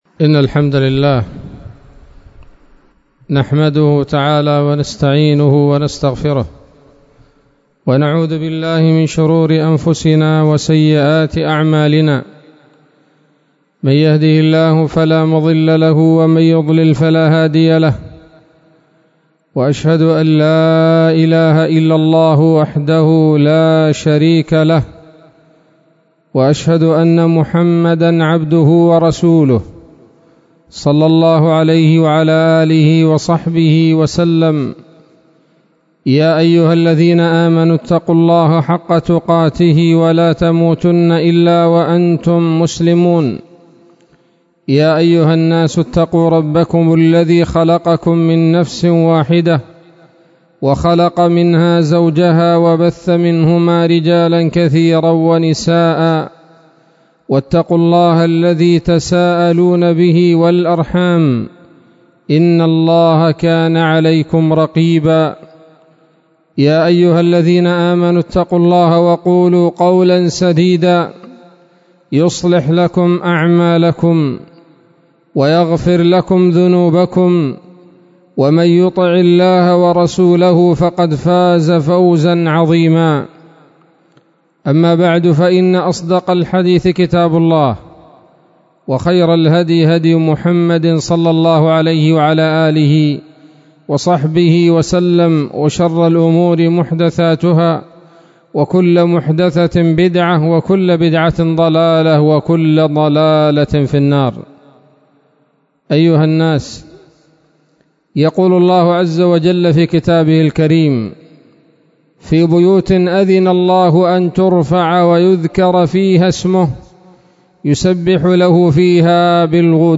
خطبة جمعة بعنوان: (( الإعلان والإذاعة بعقوبة من ترك صلاة الجماعة )) 24 رجب 1443 هـ، دار الحديث السلفية بصلاح الدين